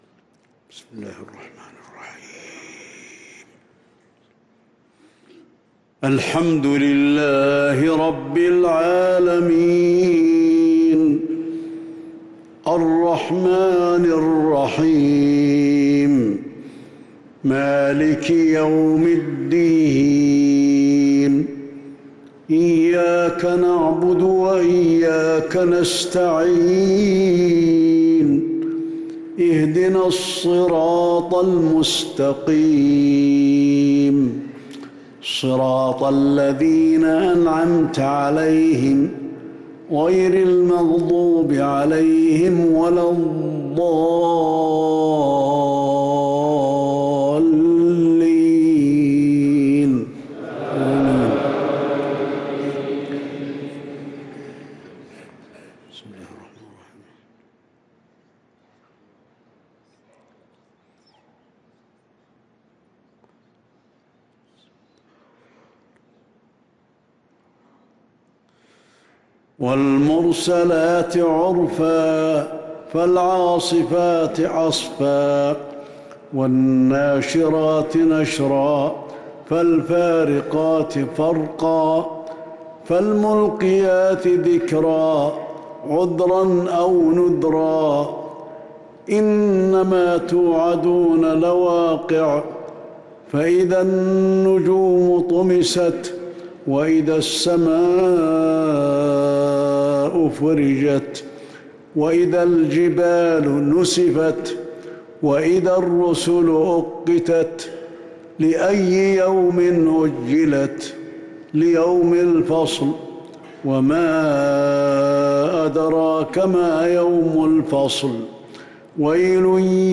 صلاة العشاء للقارئ علي الحذيفي 22 ربيع الأول 1444 هـ
تِلَاوَات الْحَرَمَيْن .